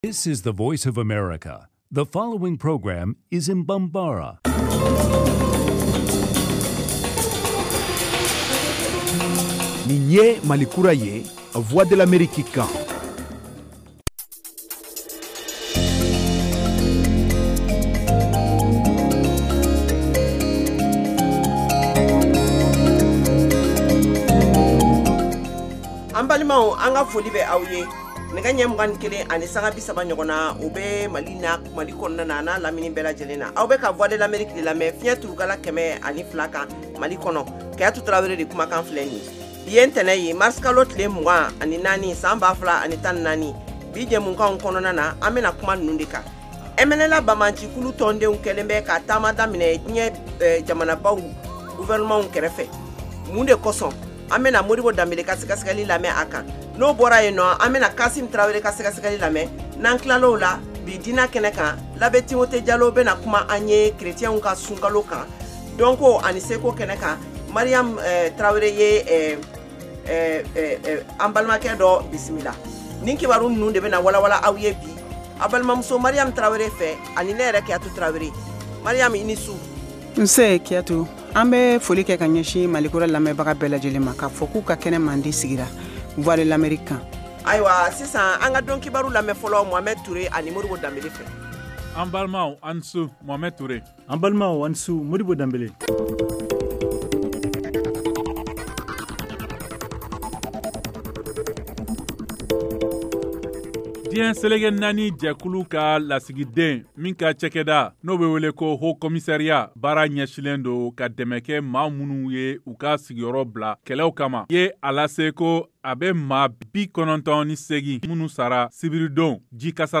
Emission quotidienne en langue bambara
en direct de Washington. Au menu : les nouvelles du Mali, les analyses, le sport et de l’humour.